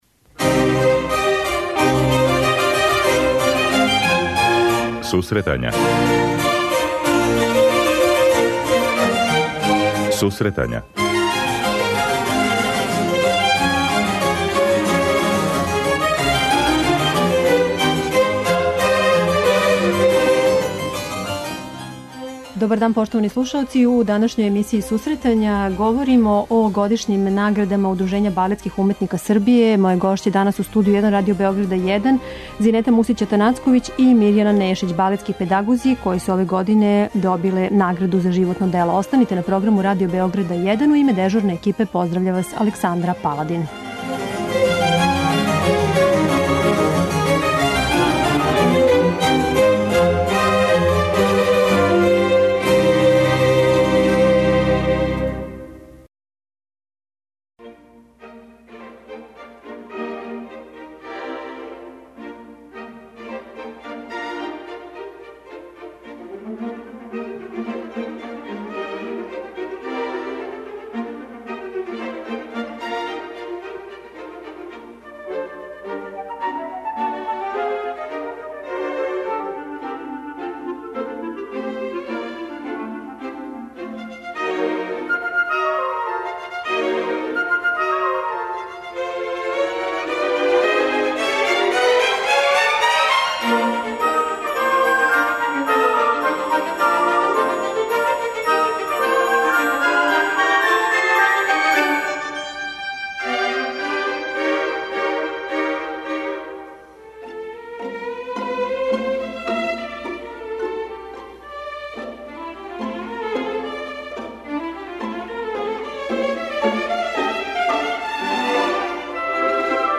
преузми : 26.05 MB Сусретања Autor: Музичка редакција Емисија за оне који воле уметничку музику.